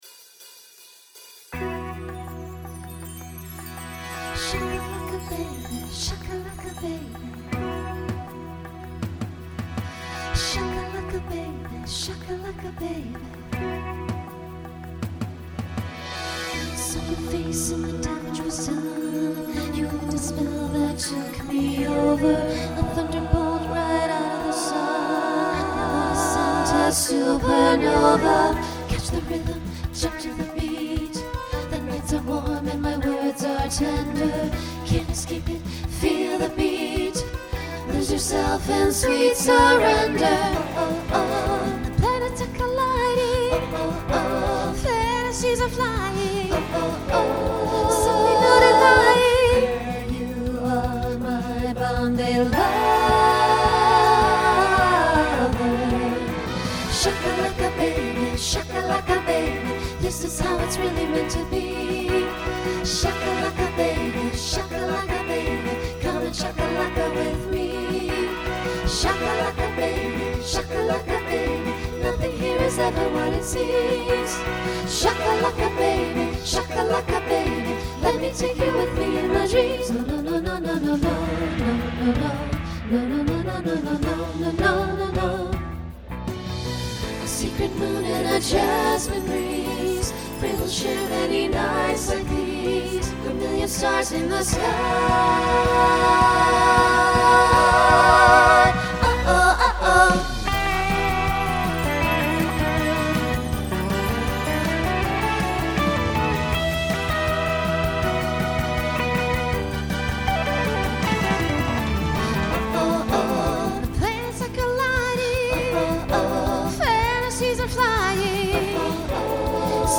Voicing SATB Instrumental combo Genre Broadway/Film